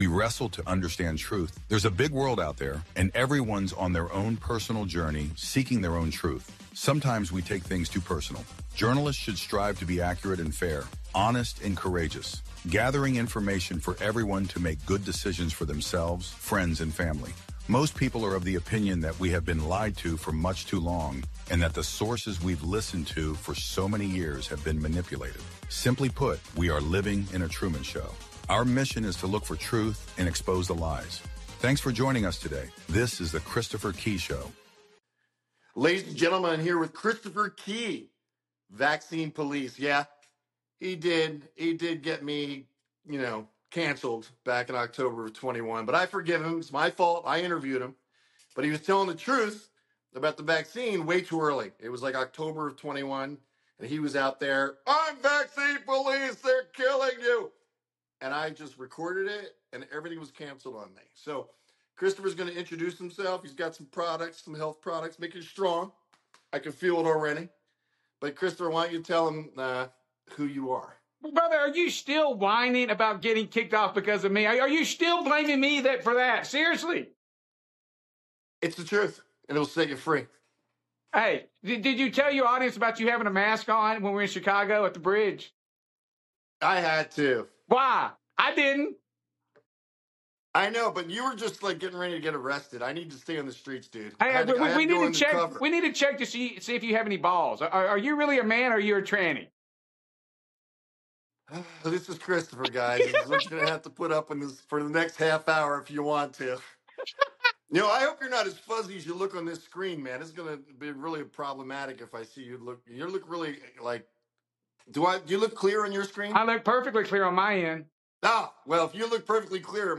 The show also includes some banter and disagreements between the hosts.